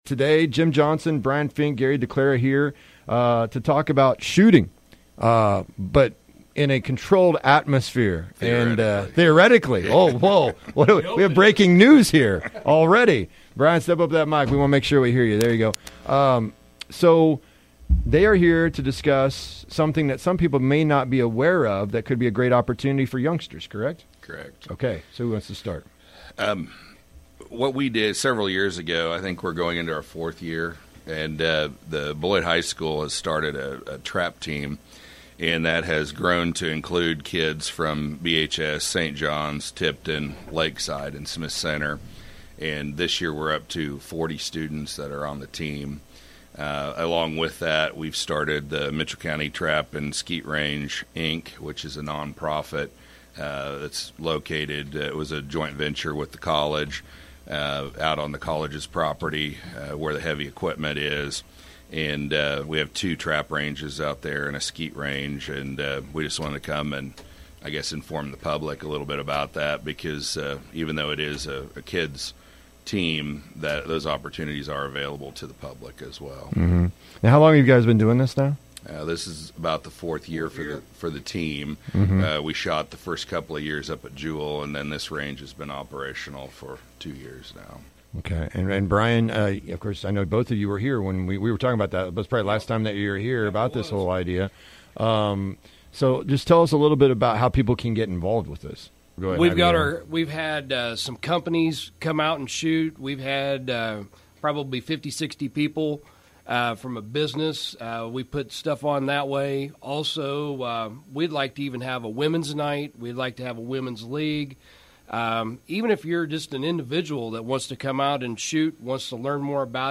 3-25-22 AREA TRAP TEAM INTERVIEW